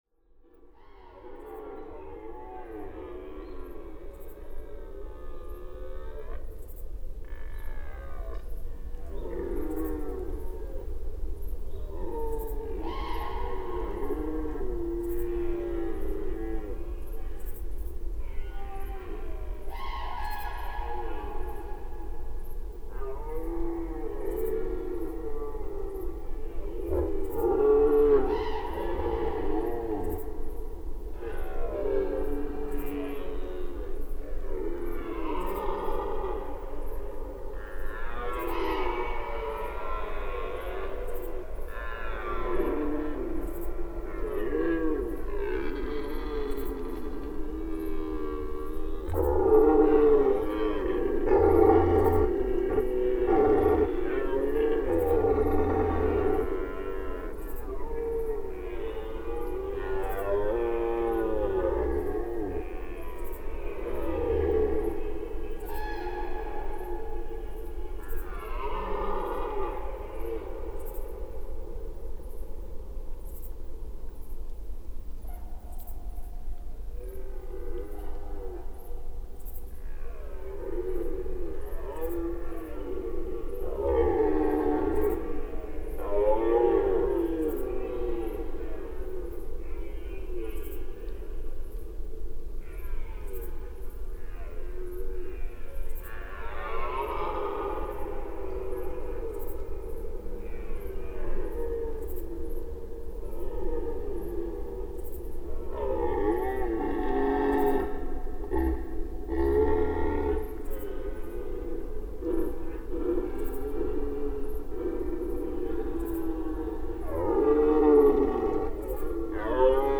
European Red Deer Rut II
September 2016 Short excerpt of a long duration recording.
PFR12071, 1-10, 160921, European Red Deer, several, advertising Wildenhainer Bruch, Germany, Sennheiser MKH 8020/30